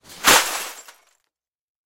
Звук разбитого стекла в мусорном мешке при выбросе в контейнер